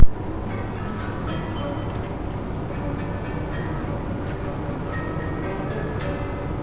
A complete set of 5th century BC bells
The 65 bells with sound
Hubei Museum, Wuhan, China